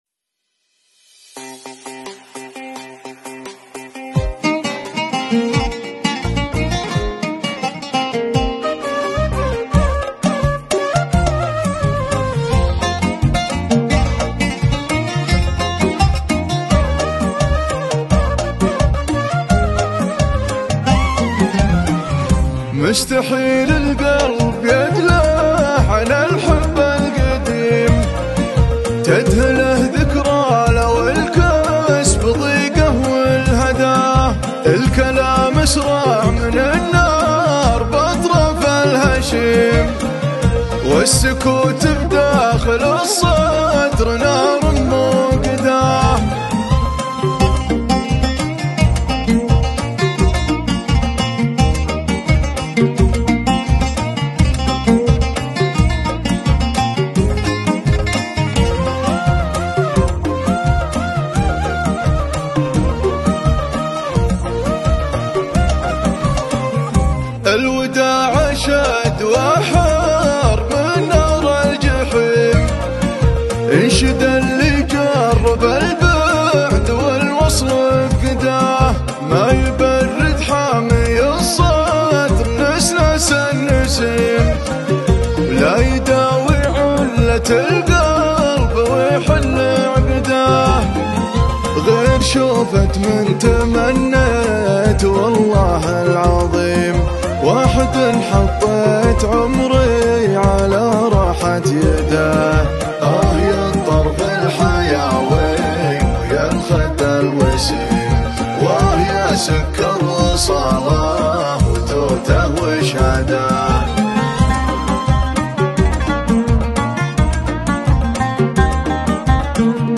شيلات طرب